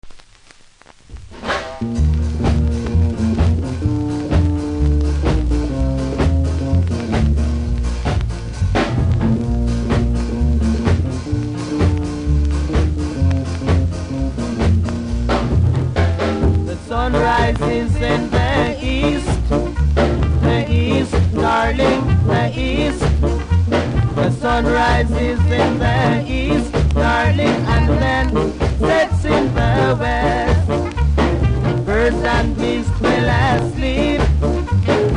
キズ多めでそれなりにノイズ感じますので試聴で確認下さい。